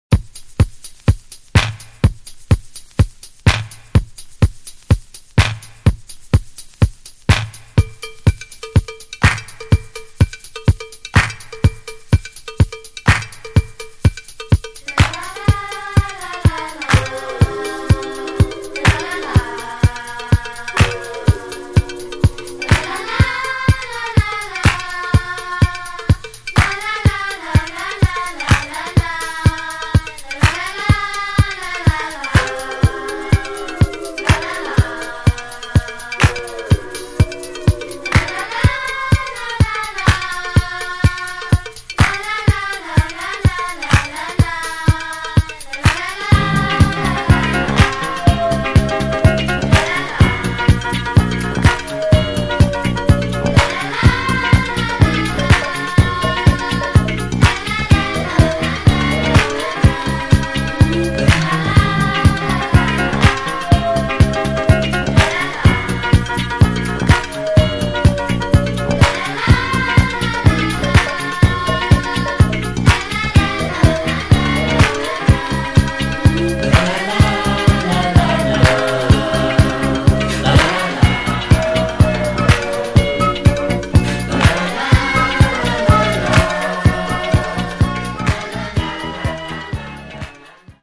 途中のトロピカルな部分はカットして、あの子供コーラス部分を主体としたジャズファンク・エディットに仕立てています。